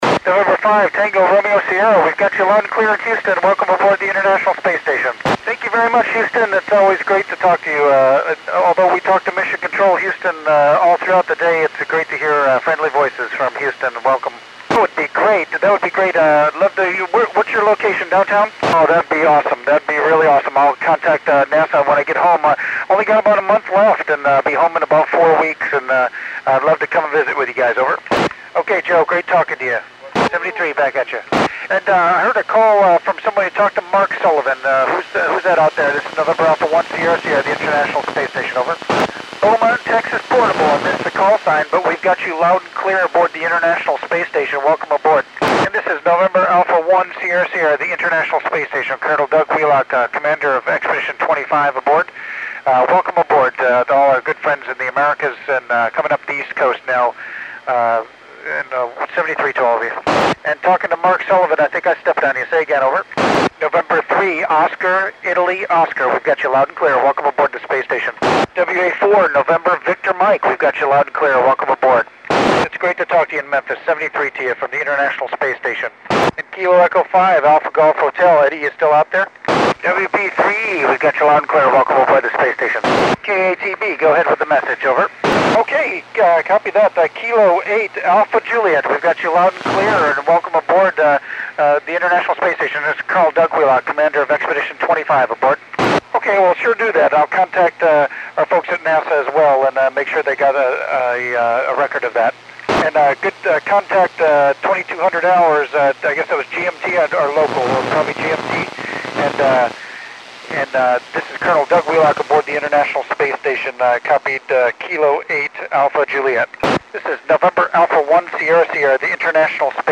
Col. Doug Wheelock (NA1SS) works U.S. stations on 03 November 2010 at 2102 UTC.